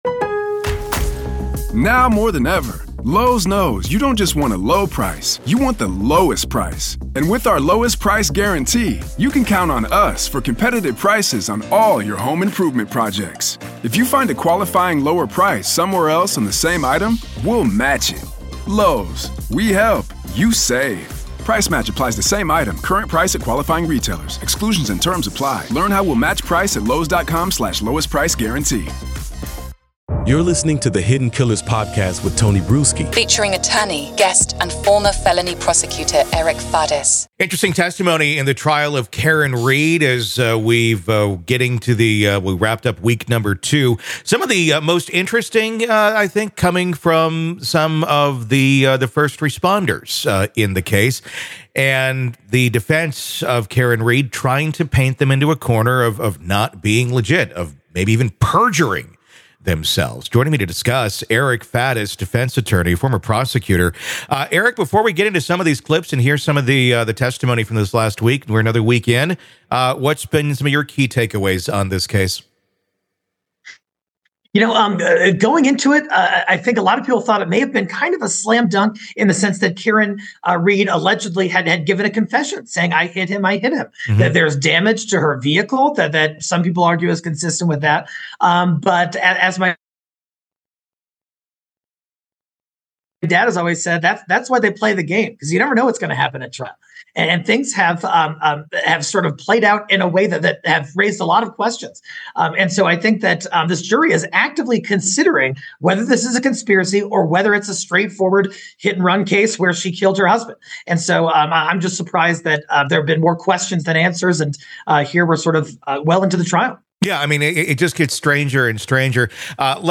Main Points of the Conversation: - **Complexity of the Trial**: Initially thought to be straightforward, the trial has raised numerous questions, making the outcome uncertain.